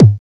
EQ KICK 5 1.wav